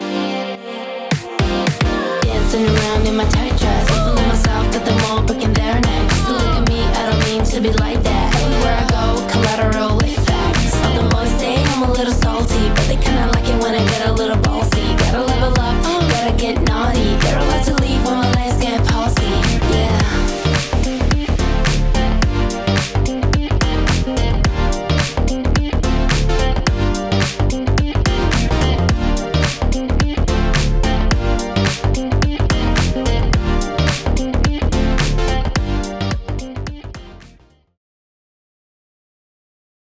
今回はシンプルな構成のデモ曲（ドラム、ベース、ギター、ボーカル）を使って、各機能を確認していきます。
エフェクト適用前後の比較
Mix Assistantを使うと、トラック同士がしっかりまとまり、音が重ならずクリアに聞こえます